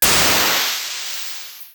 GasReleasing15.wav